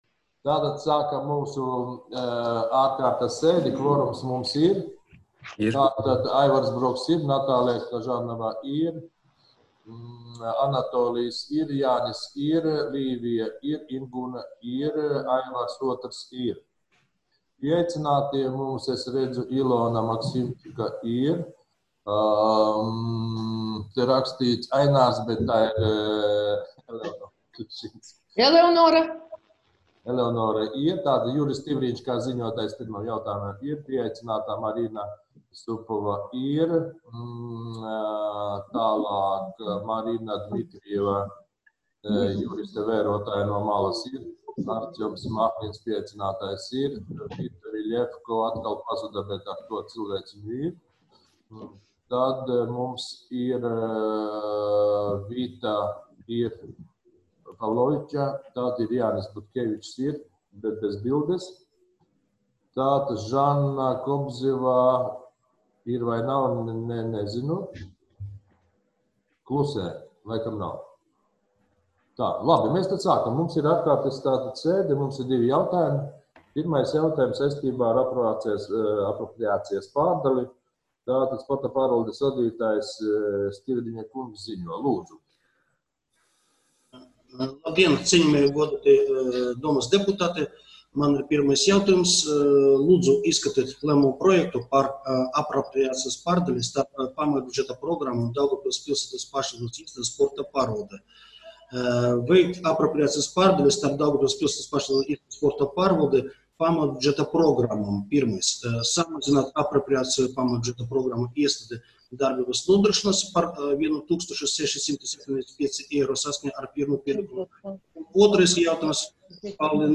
Ārkārtas Izglītības un kultūras jautājumu komitejas sēde (audio) Pašvaldības ziņas
Daugavpils pilsētas domes Izglītības un kultūras jautājumu komitejas priekšsēdētājs Aivars Broks sasauc ārkārtas Izglītības un kultūras jautājumu komitejas sēdi 2020.gada 23.aprīlī plkst.11.00 videokonferences režīmā un izsludina šādu darba kārtību: